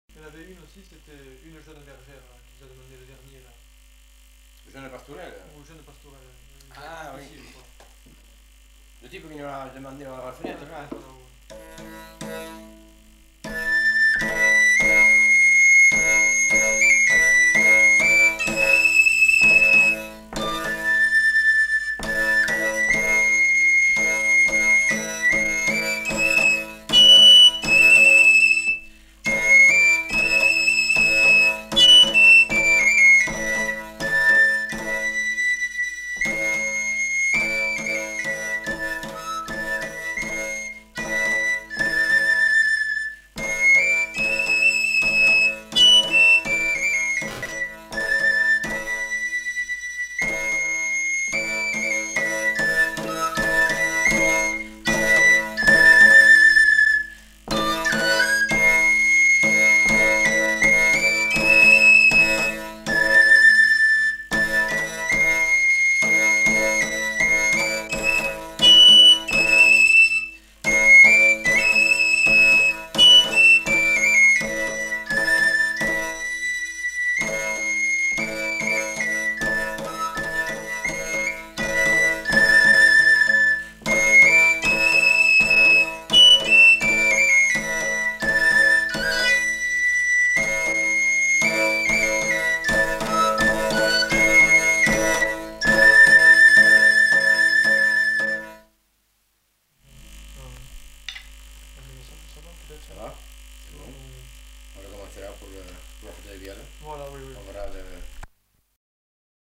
Aire culturelle : Béarn
Lieu : Bielle
Genre : morceau instrumental
Instrument de musique : tambourin à cordes ; flûte à trois trous